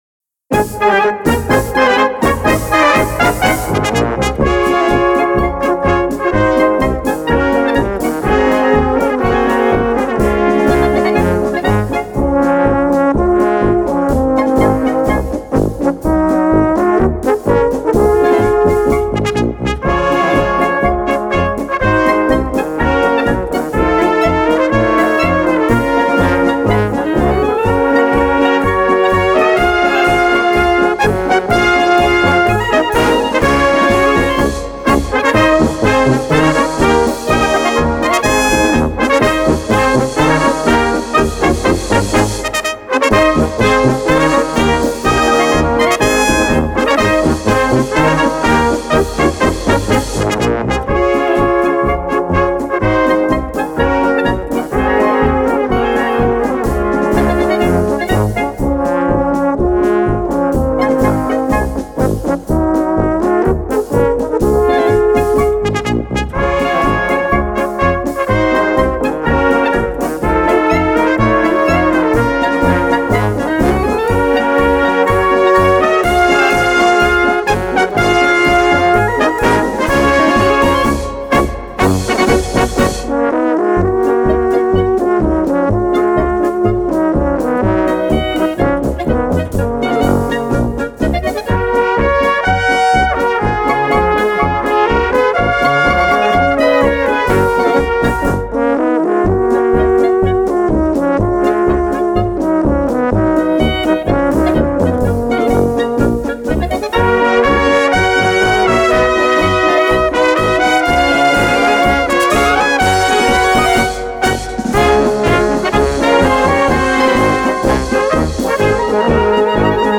Categorie Harmonie/Fanfare/Brass-orkest
Subcategorie Polka
Bezetting Ha (harmonieorkest)